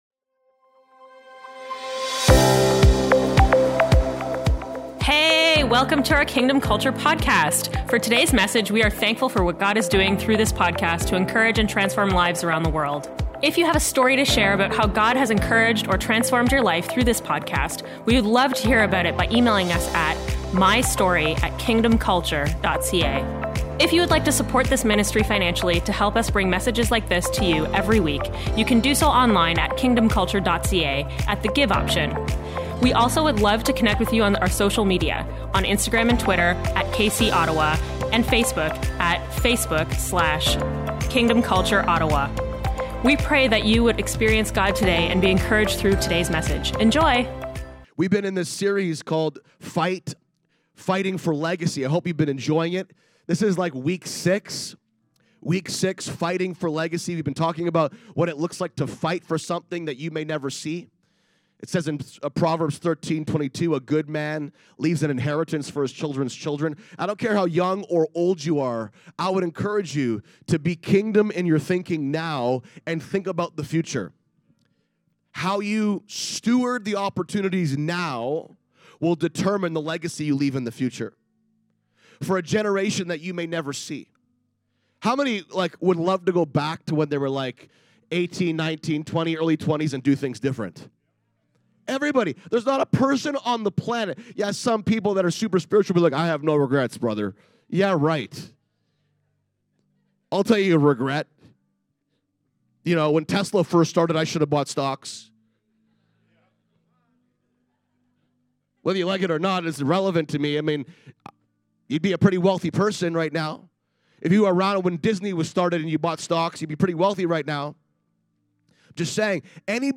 Part 6 of our Fighting for Legacy Message Series.